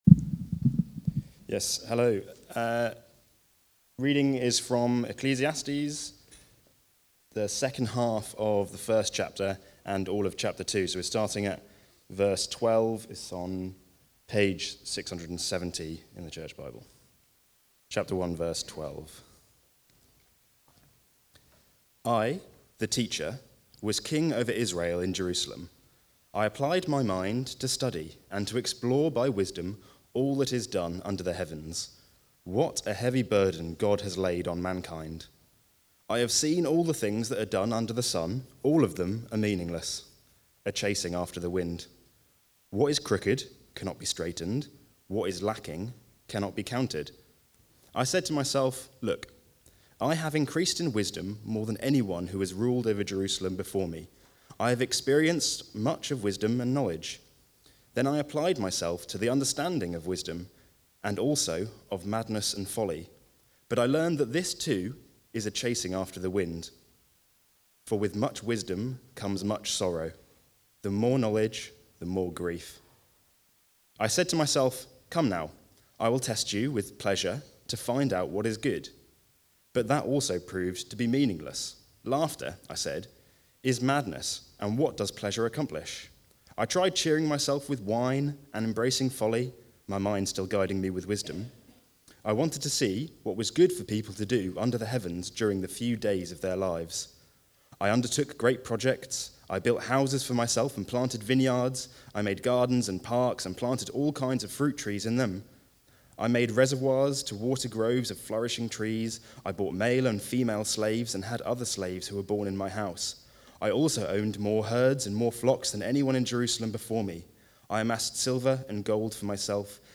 Preaching
Nothing Lasts (Ecclesiastes 1:12-2:26) from the series Chasing After the Wind. Recorded at Woodstock Road Baptist Church on 14 September 2025.